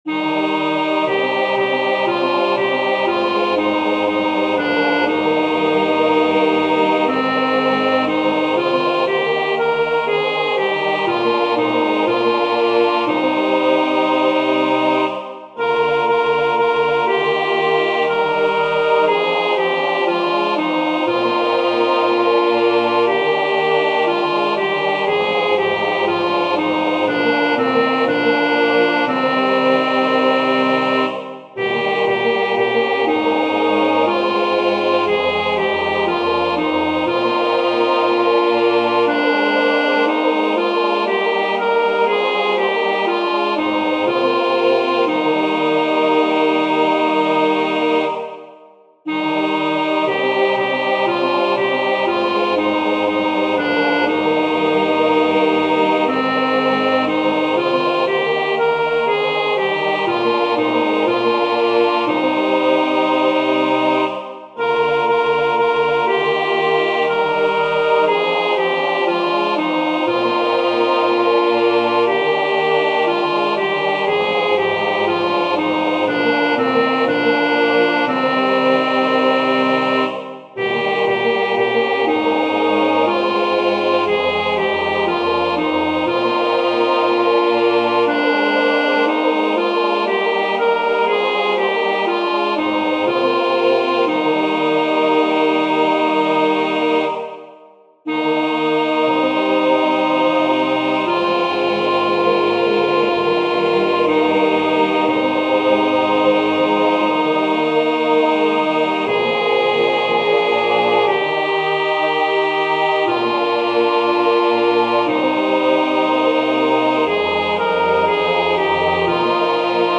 Vers 1: mf. Frasering spreekt eigenlijk wel voor zich
Vers 2: p. Let op de zinnen die soms zonder komma doorlopen.
Vanaf tweede helft: mf.
Sopraan uitgelicht